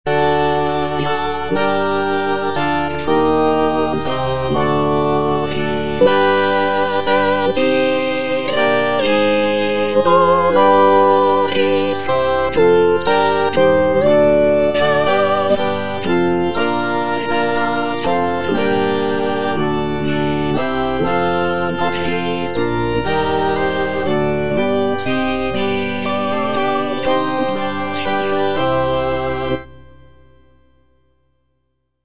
(soprano I) (Emphasised voice and other voices) Ads stop